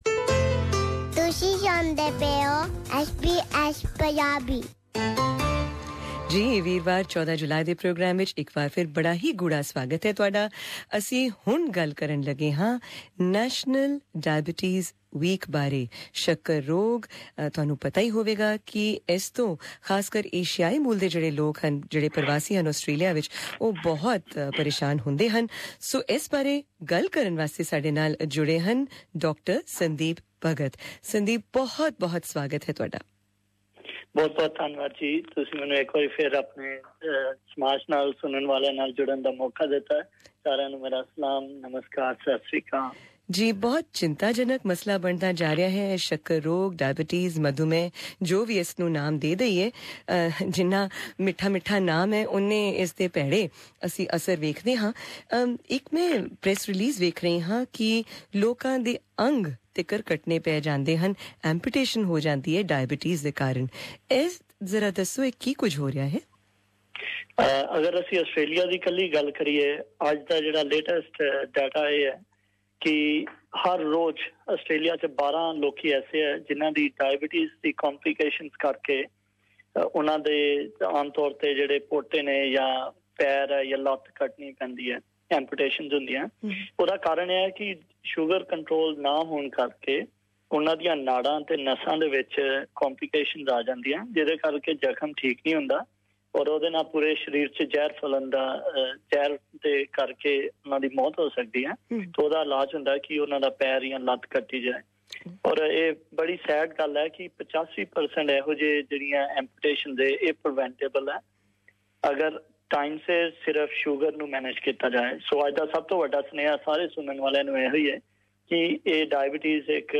Diabetes Q&A Session - SBS Punjabi talkback